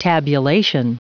Prononciation du mot tabulation en anglais (fichier audio)
Prononciation du mot : tabulation